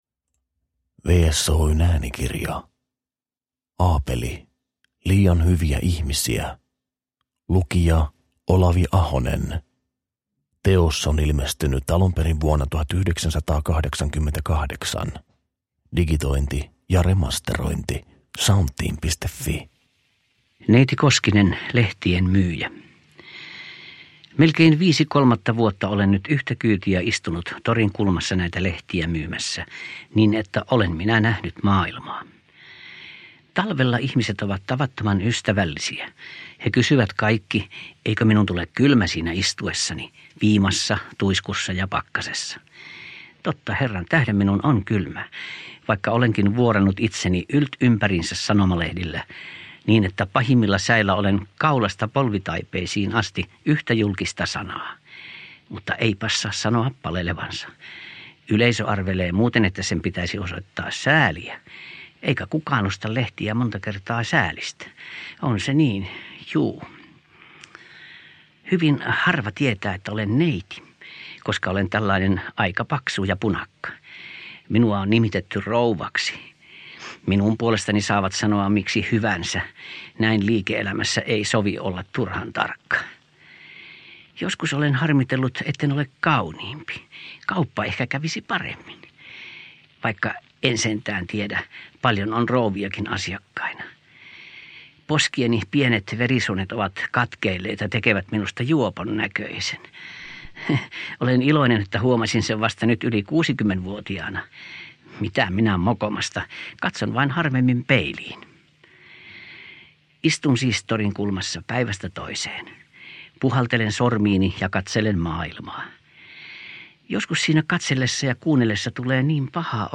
Liian hyviä ihmisiä – Ljudbok – Laddas ner
Aapelin ihmiskohtaloita aikansa etevimmän koomikon tulkitsemana.
Äänikirjaan on koottu Meidän herramme muurahaisia -romaanin parhaat monologit.
Näyttelijä, koomikko Olavi Ahonen (1923–2000) tulkitsi vuonna 1988 äänikirjaksi parhaat palat Aapelin episodimaisesta romaanista Meidän herramme muurahaisia.
Uppläsare: Olavi Ahonen